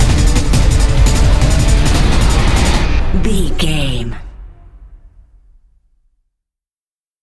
Epic / Action
Phrygian
D
brass
drum machine
percussion